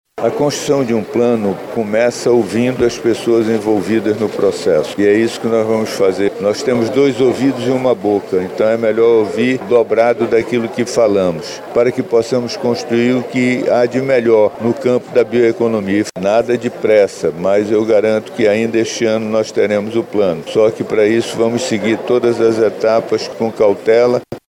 O secretário de Desenvolvimento Econômico, Ciência, Tecnologia e Inovação do Estado, Serafim Corrêa, ressalta que esta é uma das 80 escutas a serem realizadas pela pasta.